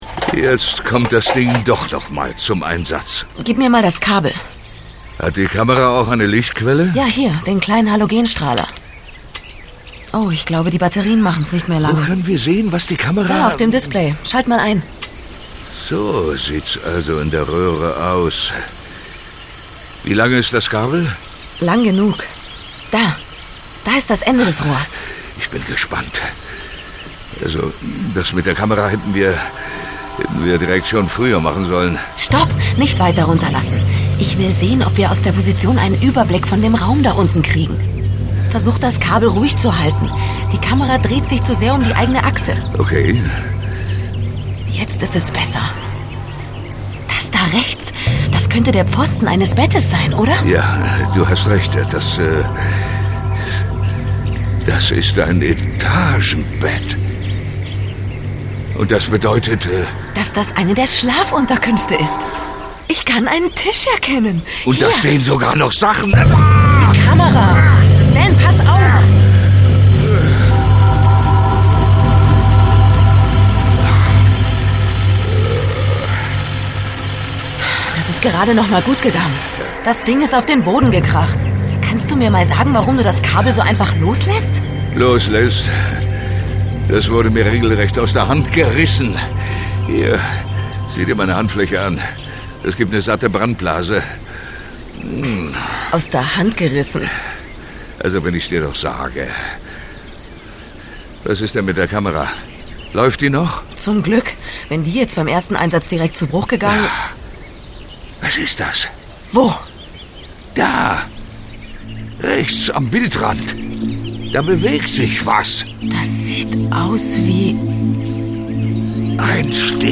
Hörspiele | Synchron